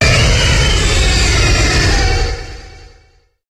Cri de Primo-Kyogre dans Pokémon HOME.